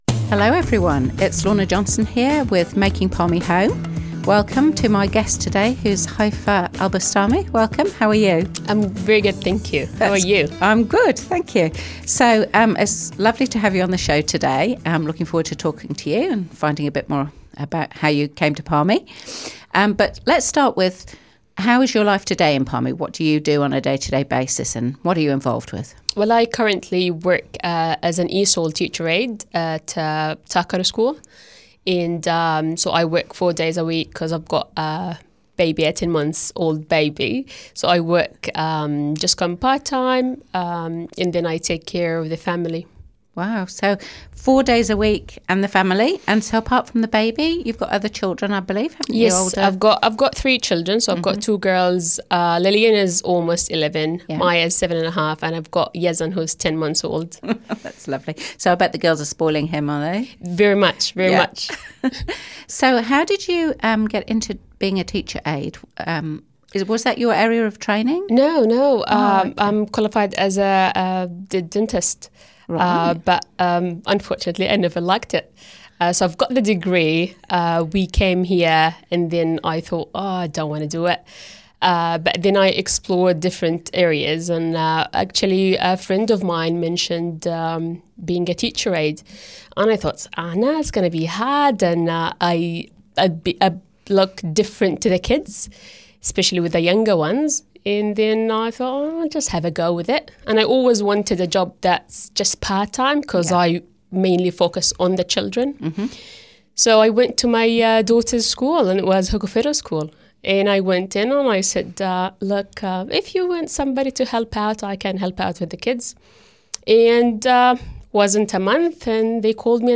Palmerston North City Councillor Lorna Johnson hosts "Making Palmy Home", a Manawatū People's Radio series interviewing migrants about their journeys to Palmerston North.
interview